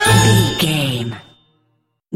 Ionian/Major
orchestra
strings
flute
drums
violin
circus
goofy
comical
cheerful
perky
Light hearted
quirky